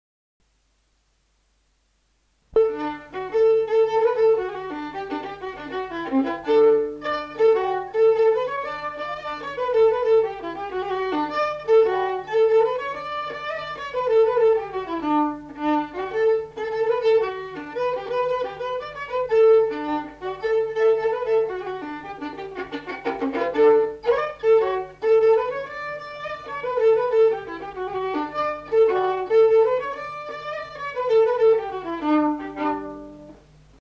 Aire culturelle : Gabardan
Genre : morceau instrumental
Instrument de musique : violon
Danse : rondeau